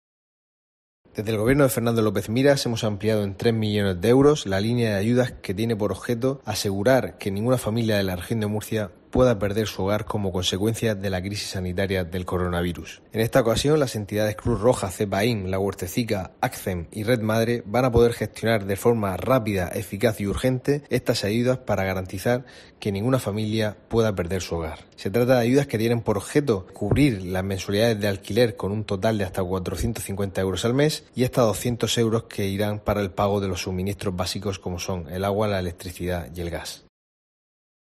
José Francisco Lajara, director general de Vivienda